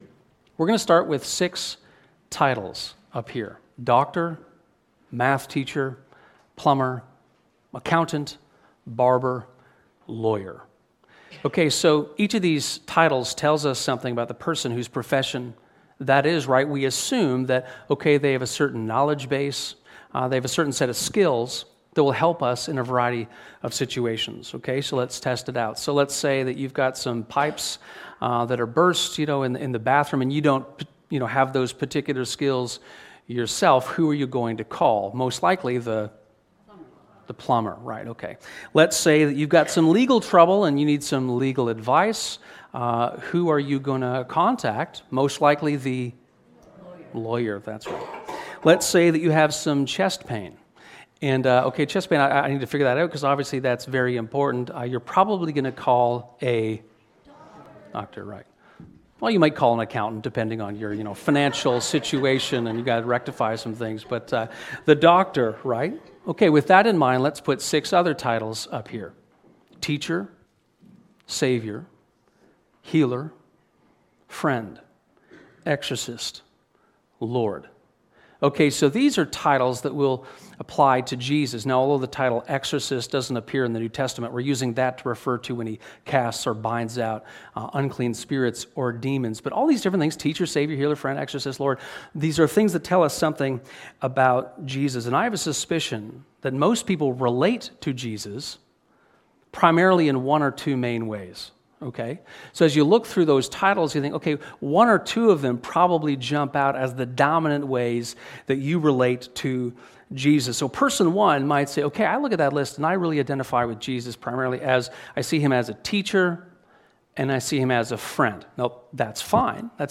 Sermons | Westminster
This sermon explores Mark 4:35-41 and the stilling of the storm.